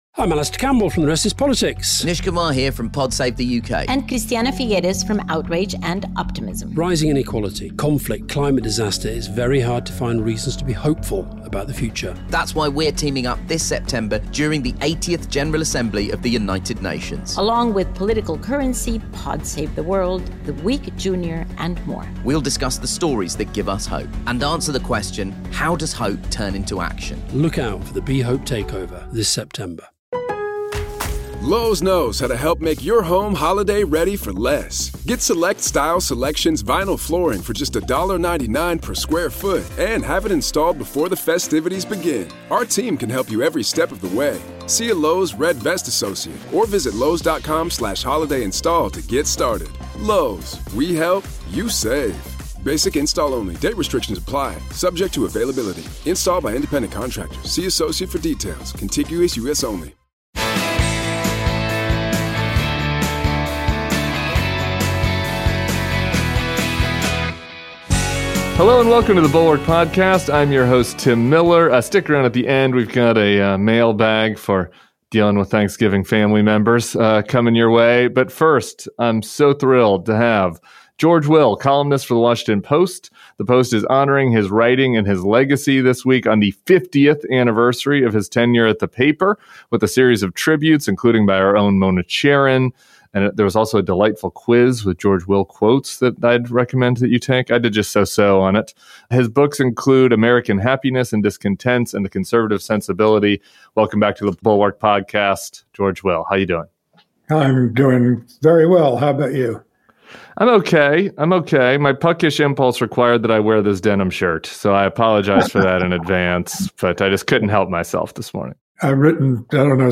Plus, Tim reads from the mailbag and serves up some advice for dealing with Trump-supporting relatives at the Thanksgiving table. George Will joins Tim Miller